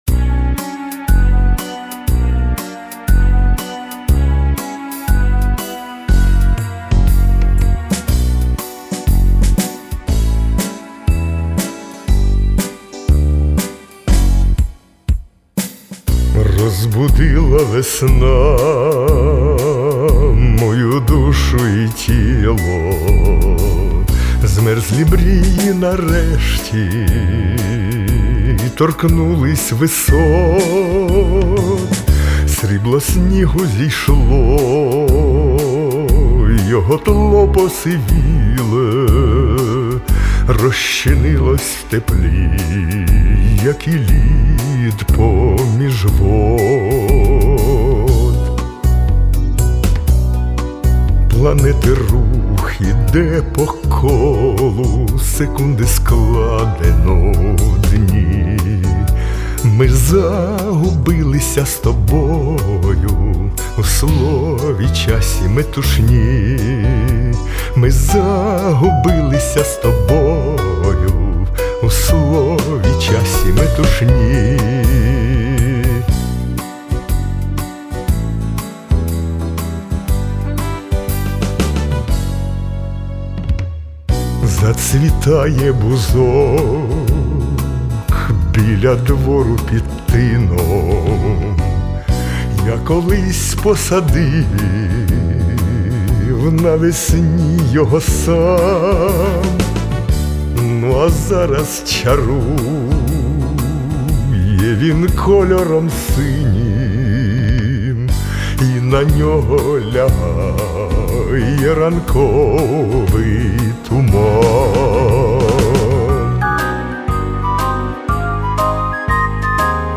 тут звучить у всій своїй баритональній соковитості